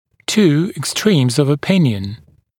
[tuː ɪks’triːmz əv ə’pɪnjən] [ek-][ту: икс’три:мз ов э’пинйэн] [эк-]две крайние точки зрения